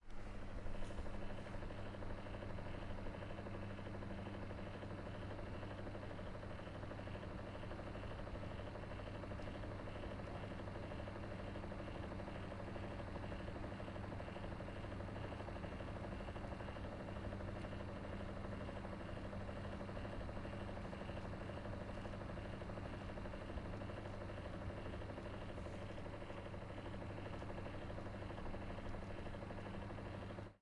风扇吹动 " 风扇(加热)
描述：在房子的地下室的加热风扇。录音是从风扇后面进行的，因此空气不会直接吹入麦克风。 用Zoom H1记录。
Tag: 空调 风扇 风扇 风扇 空气 通风 吹制 AC 通风口 弗利